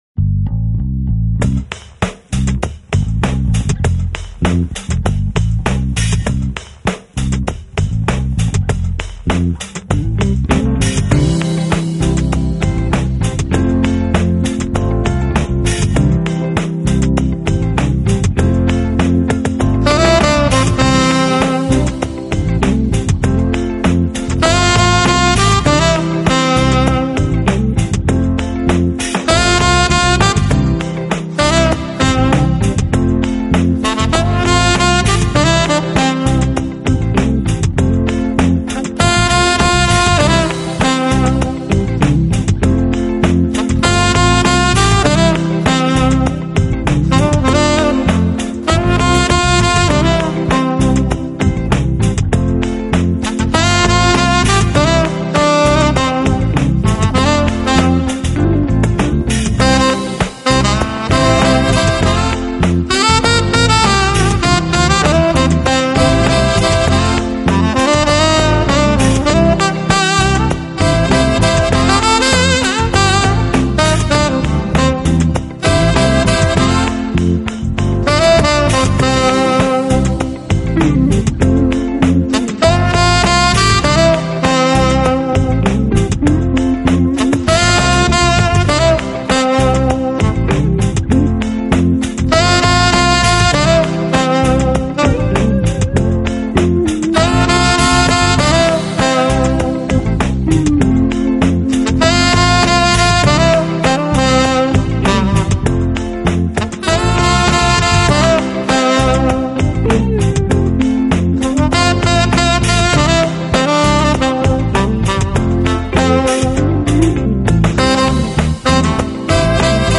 Genre: Jazz, Smooth Jazz
Our music is sensuous, soothing, relaxing, yet invigorating.